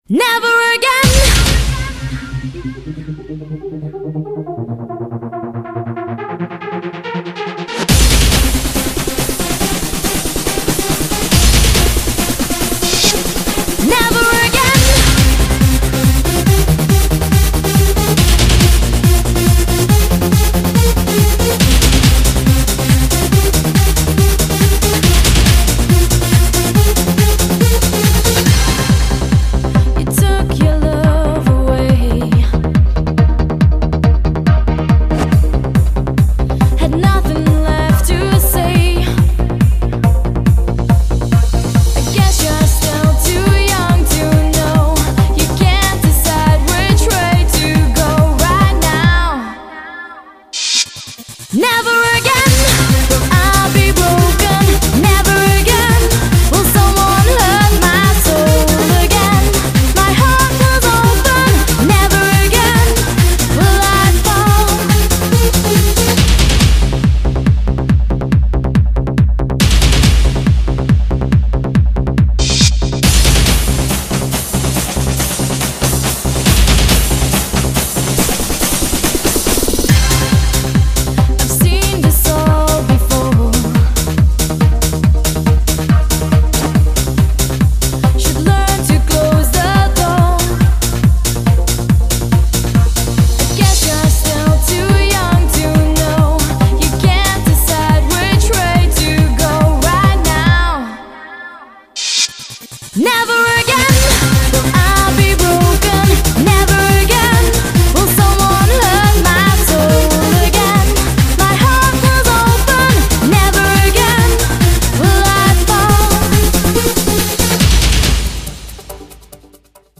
BPM140-140
Audio QualityPerfect (High Quality)